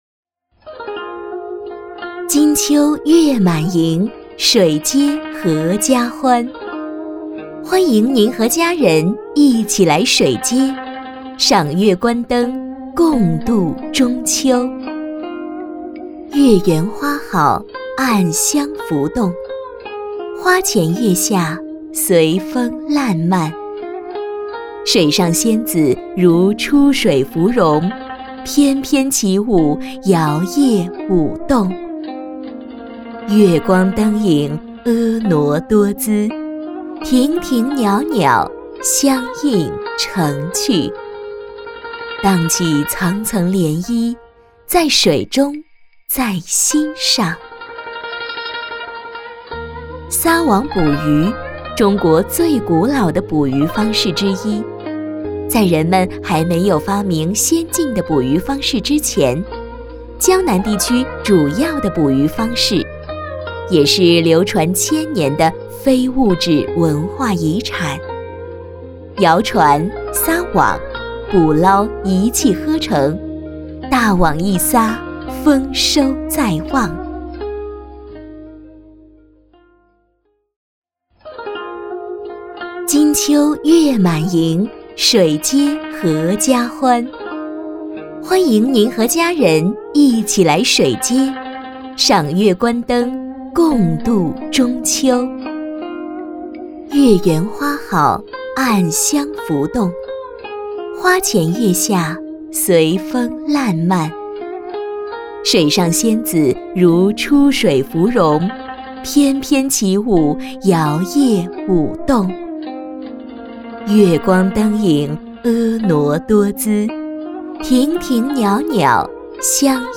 女S126 国语 女声 旁白解说-中秋晚会舞台主持【朗诵抒情】〖甜度★★★☆〗 低沉|积极向上|时尚活力|神秘性感|调性走心|亲切甜美|感人煽情|素人